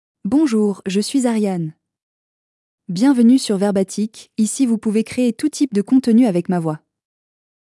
Ariane — Female French (Switzerland) AI Voice | TTS, Voice Cloning & Video | Verbatik AI
Ariane is a female AI voice for French (Switzerland).
Voice sample
Listen to Ariane's female French voice.
Ariane delivers clear pronunciation with authentic Switzerland French intonation, making your content sound professionally produced.